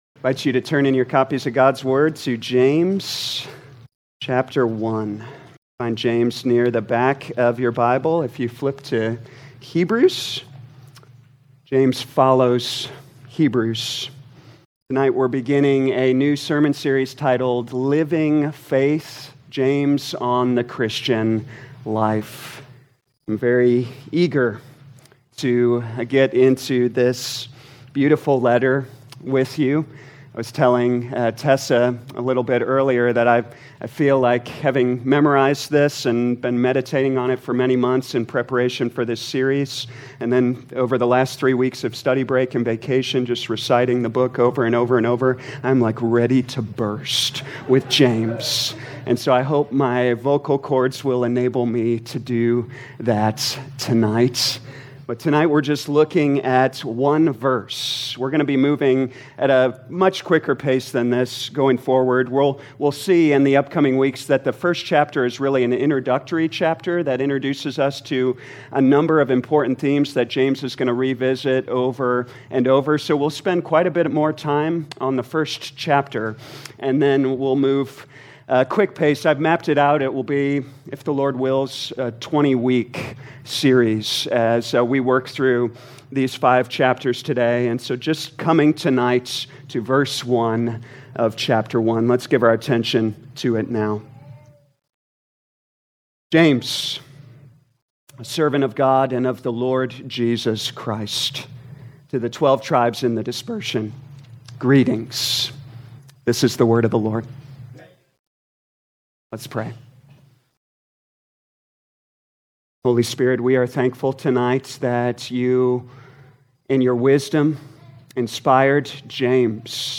2025 James Evening Service Download